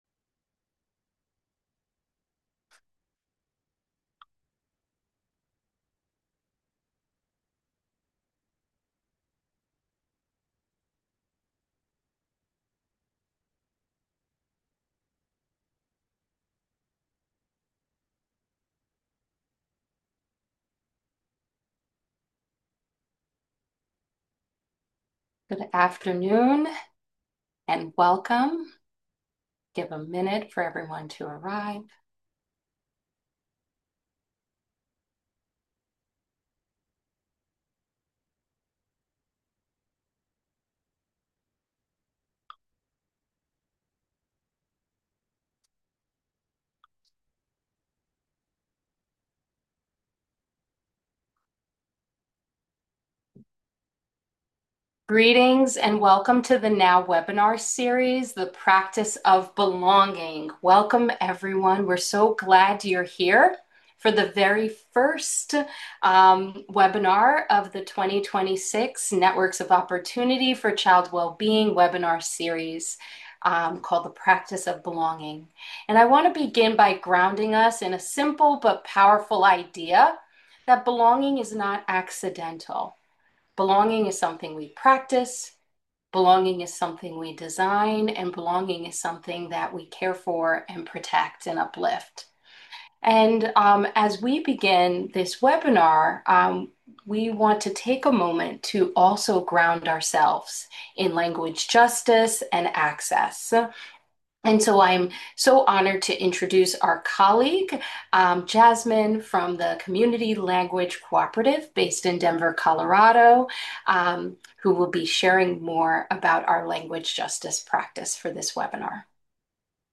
VIRTUAL LEARNING LAB ARCHIVE
Este seminario web explora la dignidad y la comunidad querida en la práctica de la promoción de políticas, específicamente dentro del contexto de los esfuerzos continuos para proteger y defender la dignidad de los niños y las familias inmigrantes.